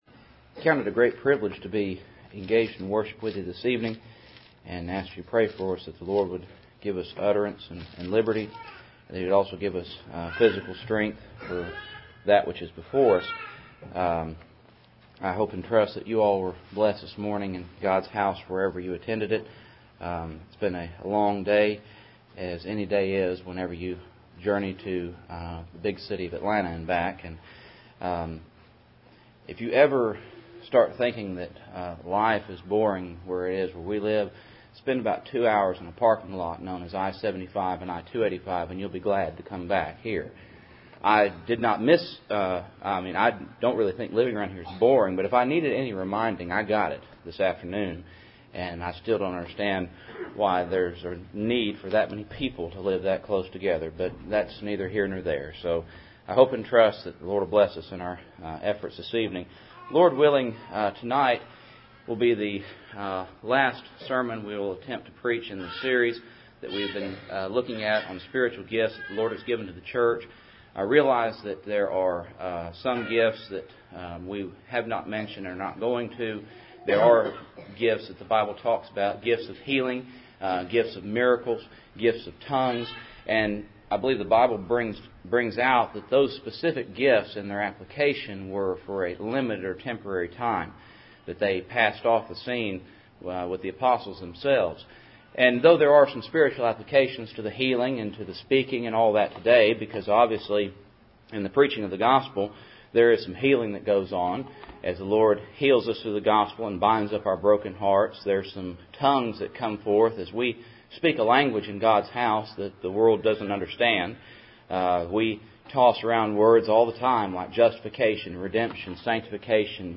Passage: Romans 12:8 Service Type: Cool Springs PBC Sunday Evening